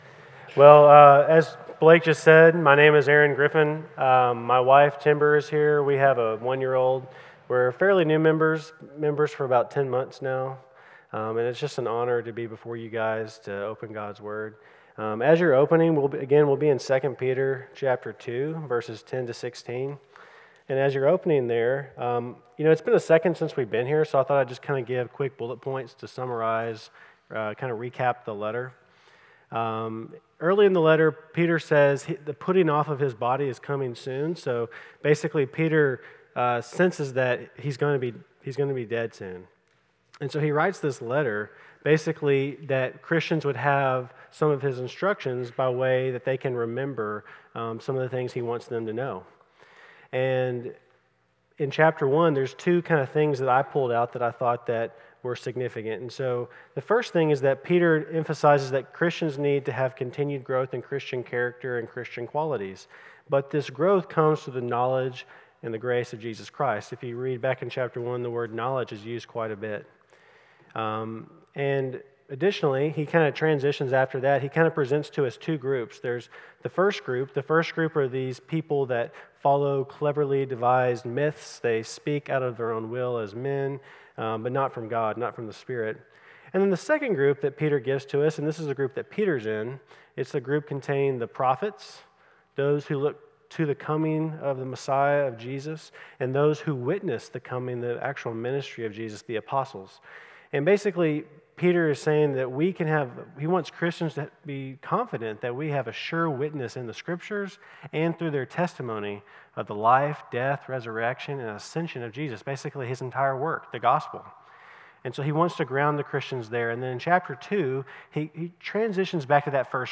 CCBC Sermons 2 Peter 2:10-16 Oct 06 2024 | 00:26:15 1x 00:00 / 00:26:15 Subscribe Share Apple Podcasts Spotify Overcast RSS Feed Share Link Embed